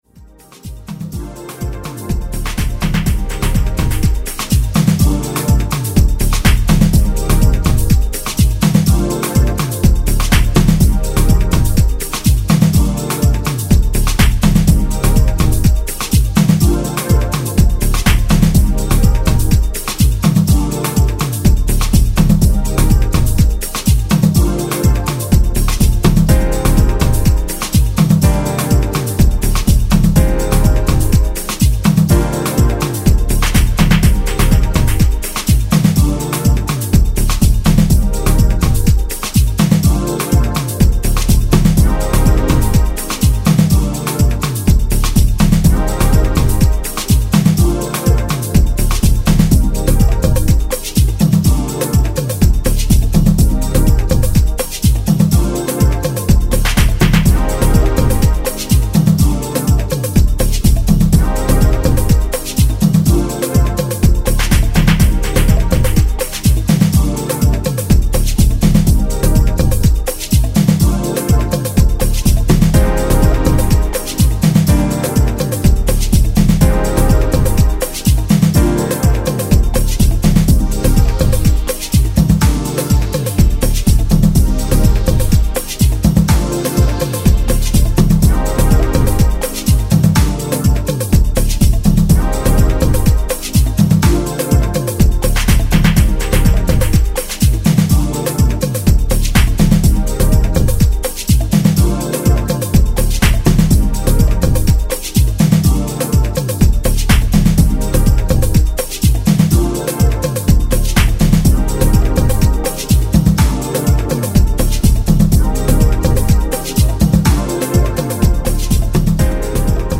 Vocal Instrumental